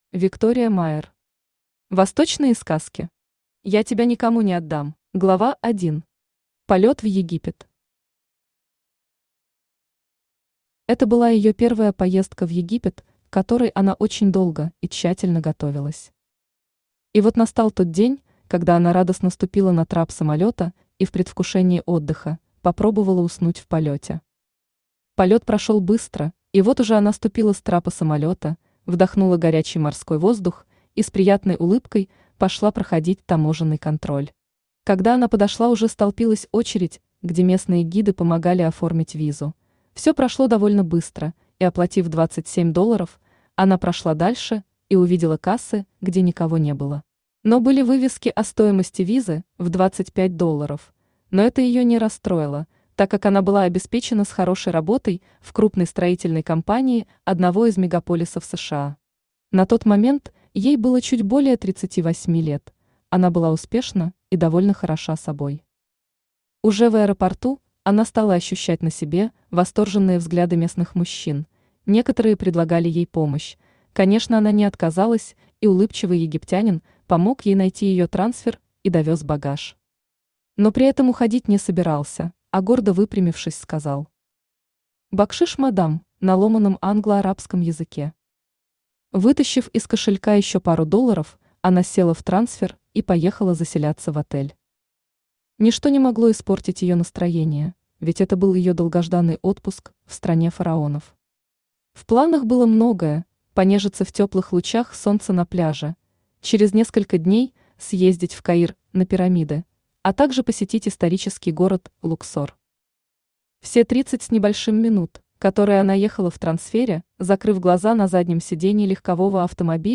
Автор Виктория Майер Читает аудиокнигу Авточтец ЛитРес.